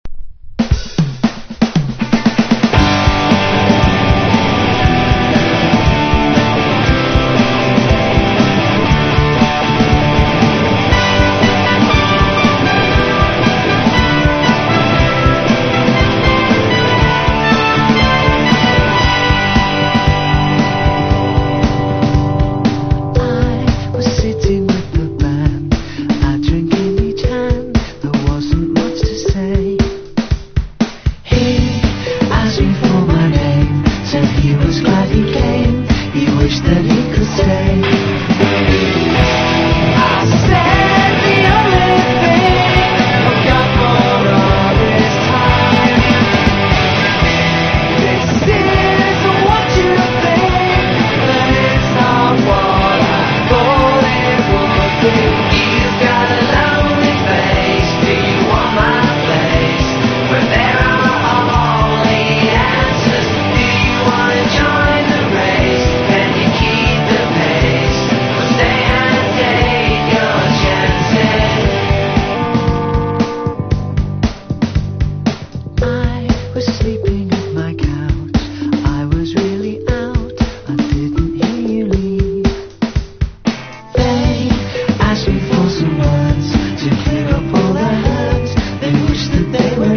1. 90'S ROCK >
NEO ACOUSTIC / GUITAR POP